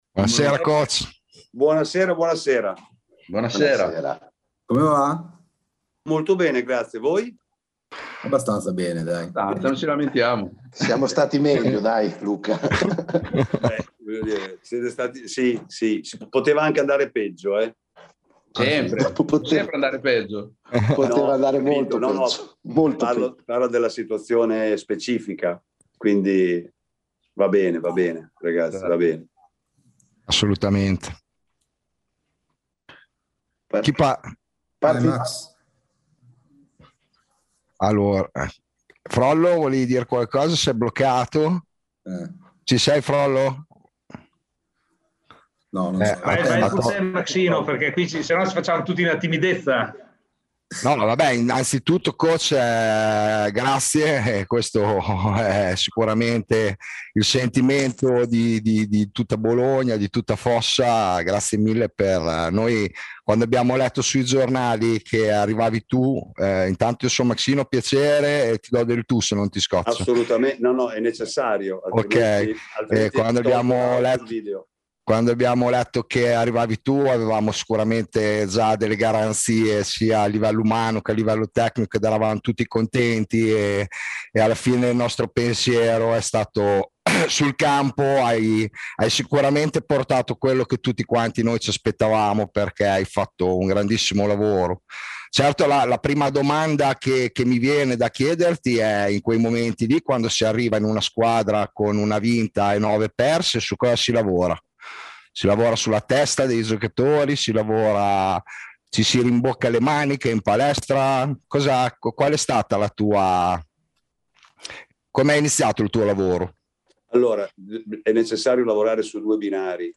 Intervista
in diretta a FOSSA ON THE RADIO